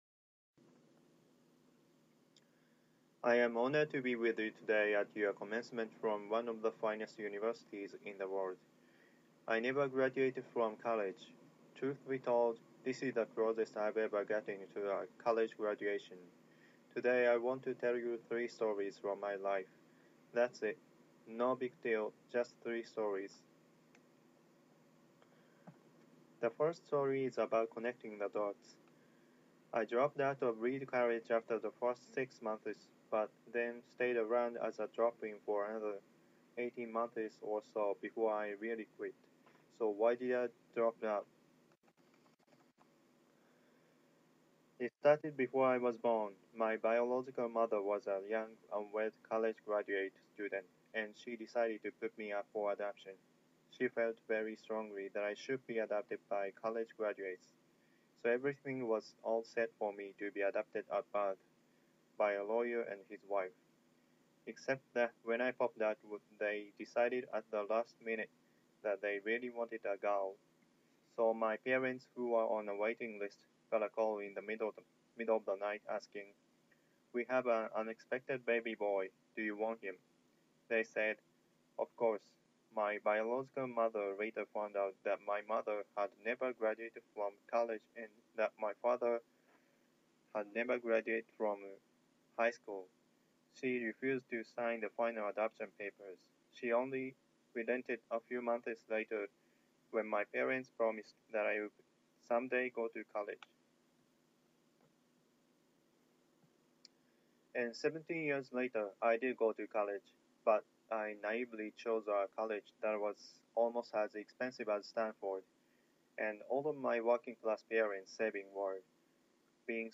Steeve Jobs Speech Part 1